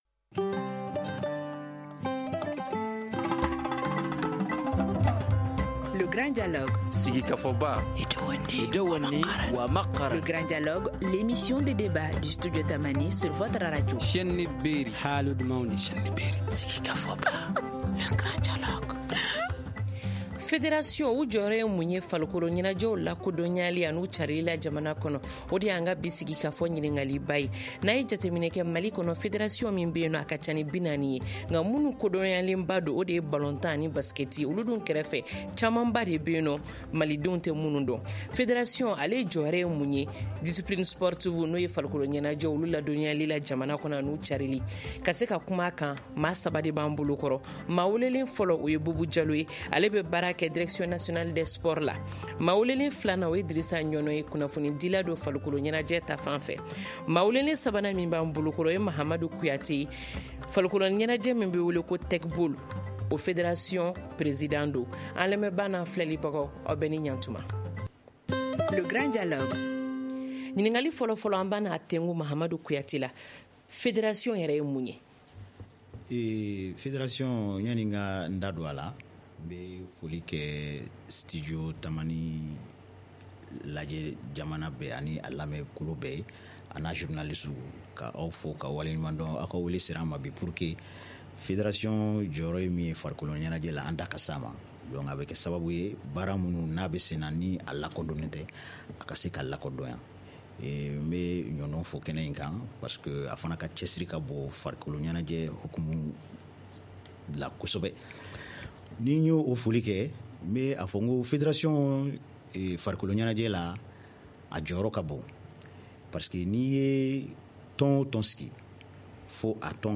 Quels sont les rôles des fédérations sportives ? Quels sont les défis à relever par ces organisations ? Le Grand Dialogue ouvre le débat.